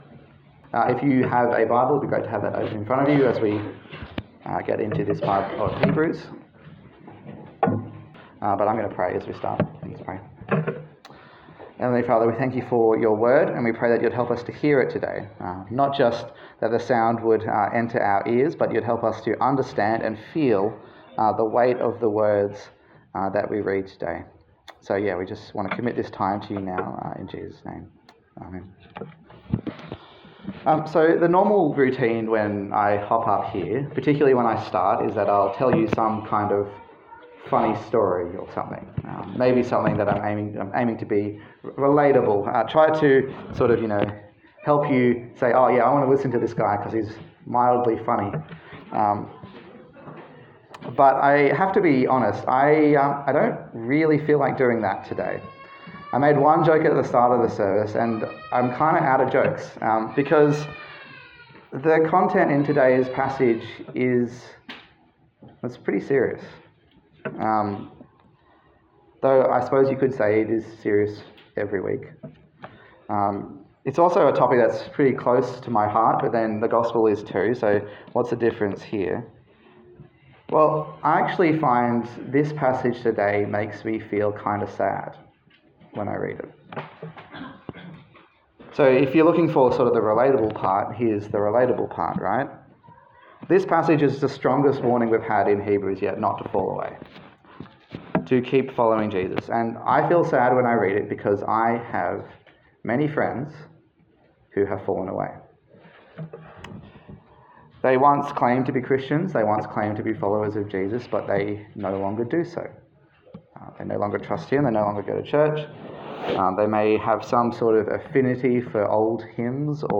Hebrews Passage: Hebrews 5:11-6:20 Service Type: Sunday Service